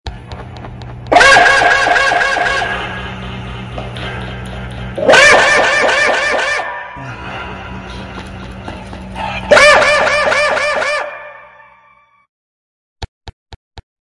Chiki Barking Sad Sound Effect Download: Instant Soundboard Button